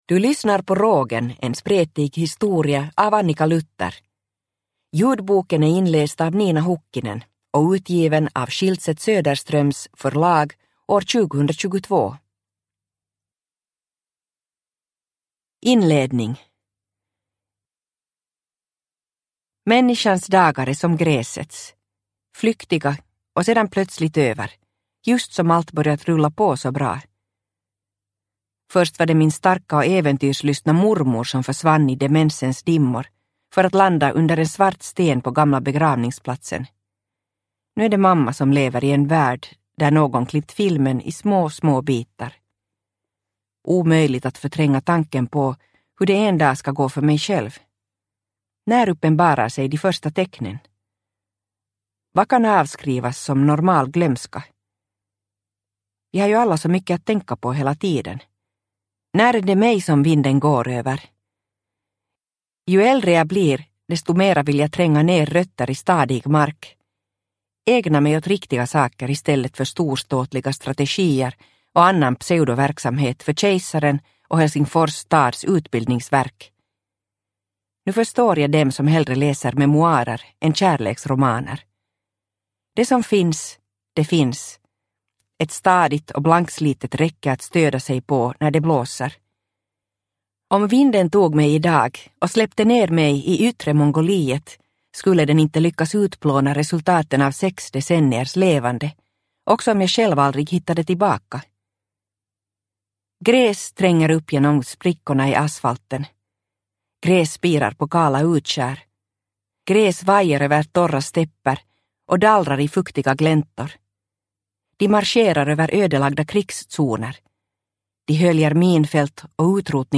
Rågen. En spretig historia – Ljudbok – Laddas ner